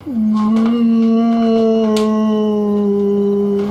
Memes
Dwight Crying At Night